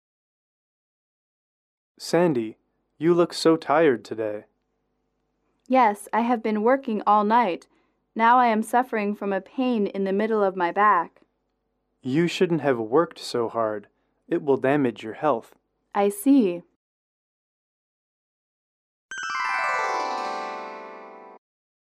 英语口语情景短对话16-2：超时工作(MP3)